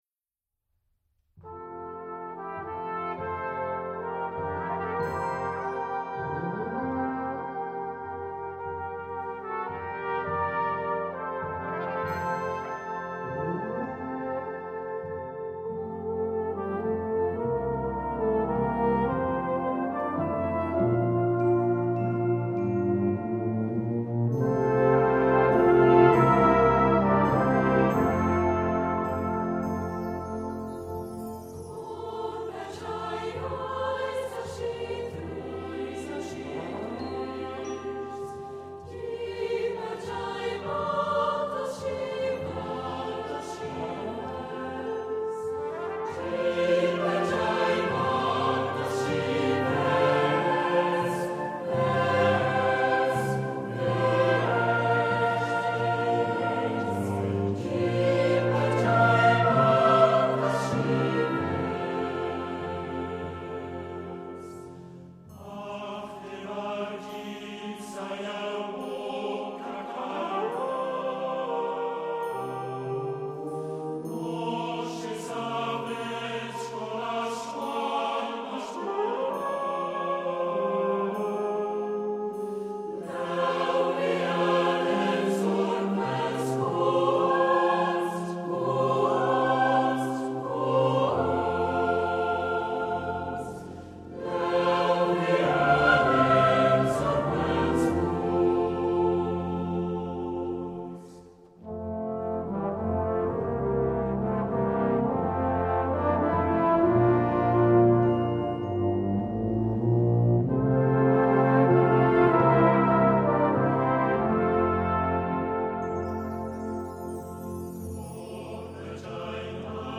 Brass Band & Chor